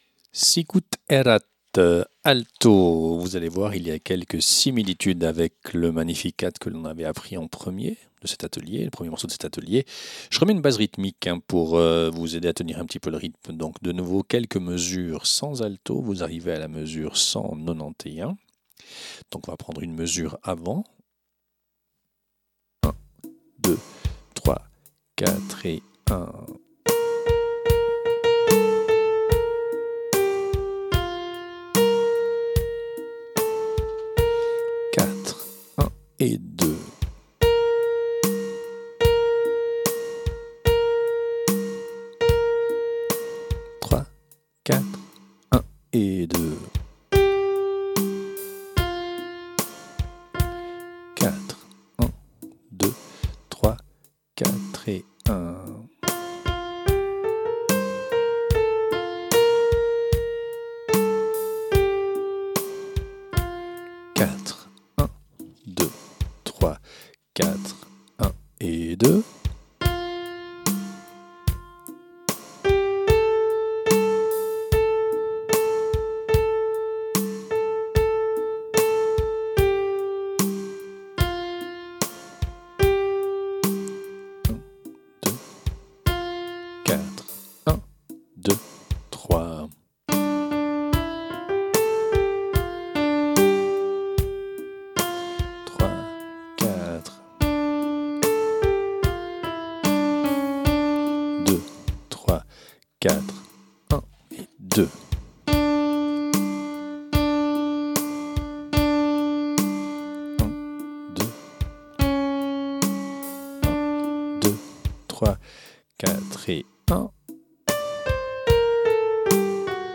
Répétition SATB par voix
Alto
Sicut Erat Alto.mp3